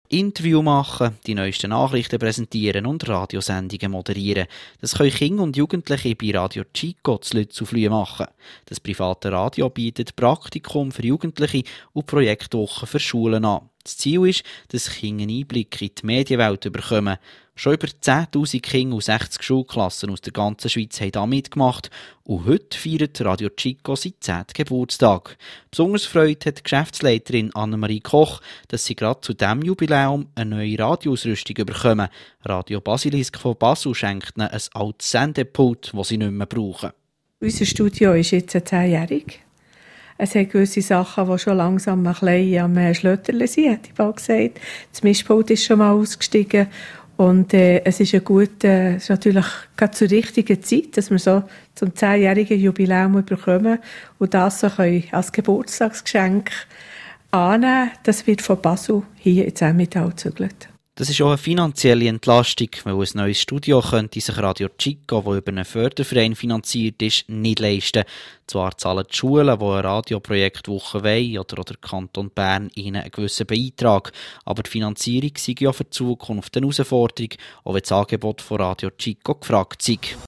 Am 8. Januar haben wir in unserem Studio den 10. Geburtstag von RadioChico Schweiz gefeiert.
Interview